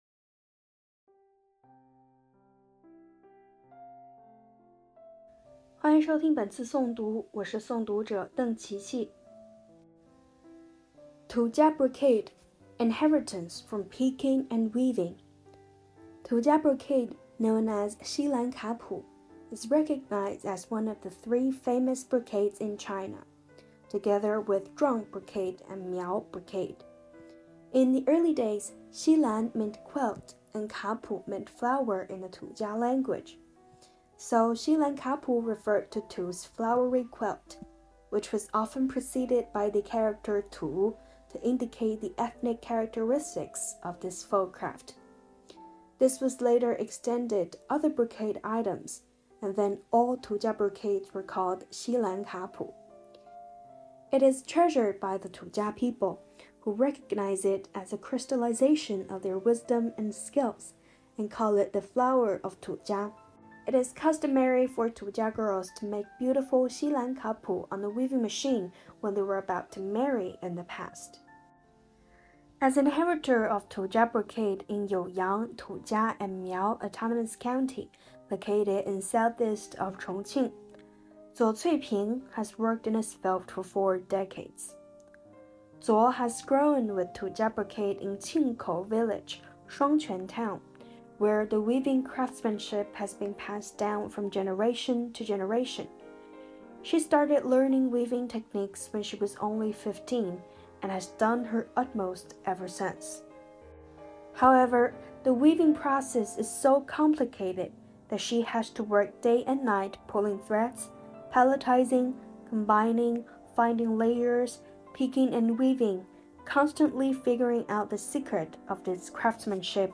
Voice-over